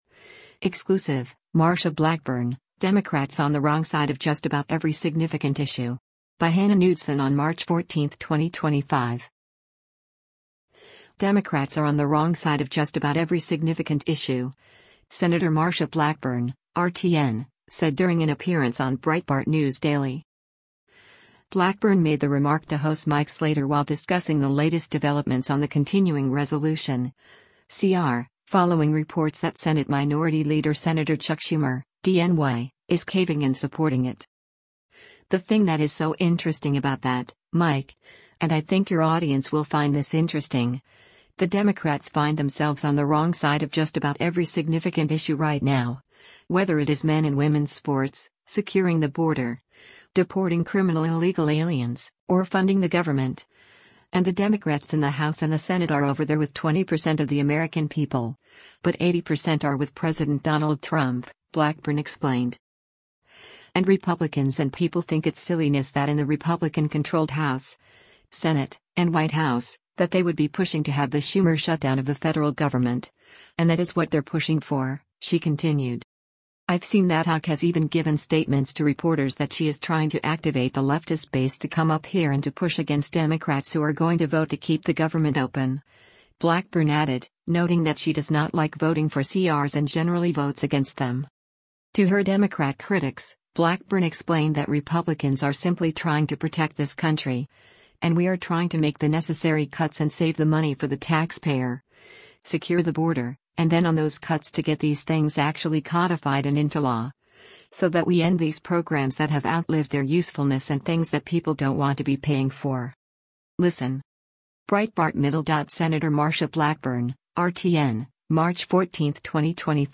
Democrats are “on the wrong side of just about every significant issue,” Sen. Marsha Blackburn (R-TN) said during an appearance on Breitbart News Daily.